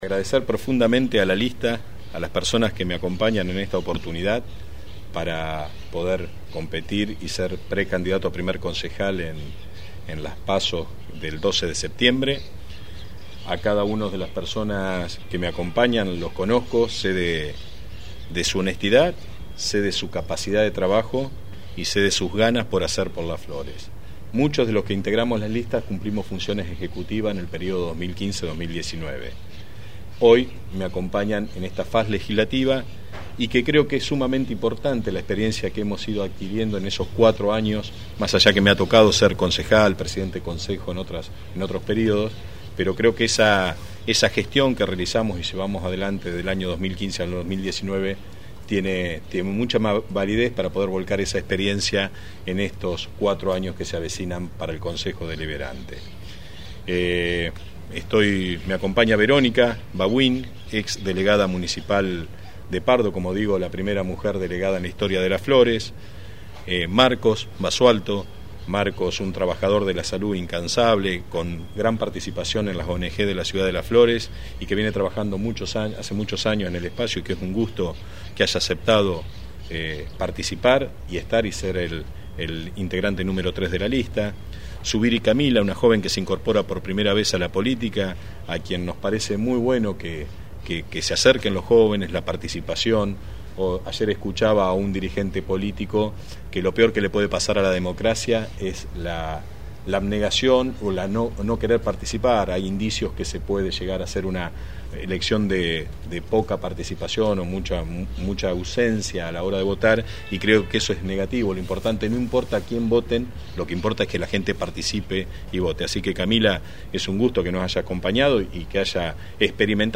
En la mañana de hoy jueves fue presentada en rueda de prensa la lista local de Juntos para las PASO 2021, que lleva al ex intendente municipal, Esc. Ramón Canosa, como candidato a concejal titular en primer término.